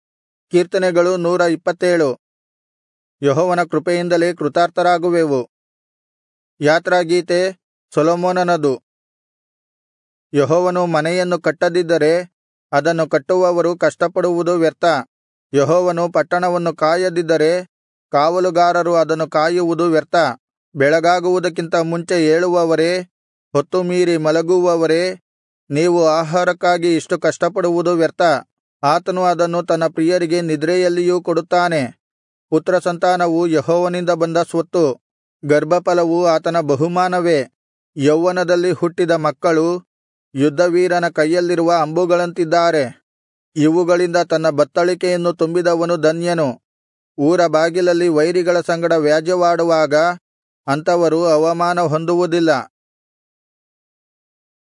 Kannada Audio Bible - Psalms 6 in Irvkn bible version